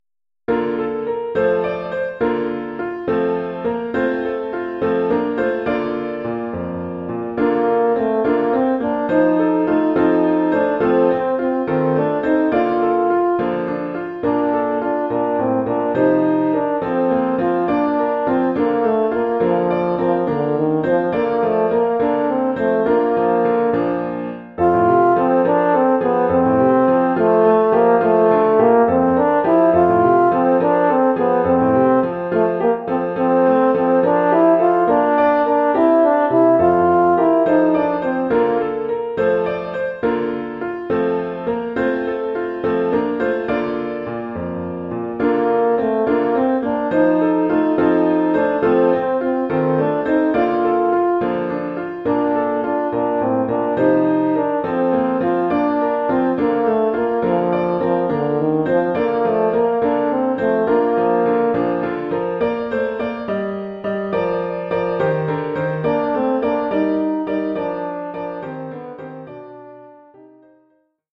Oeuvre pour saxhorn alto et piano.